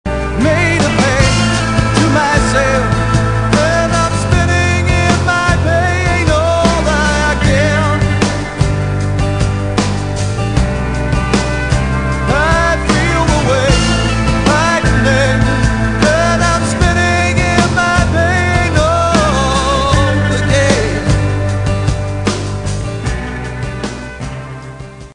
pedal steel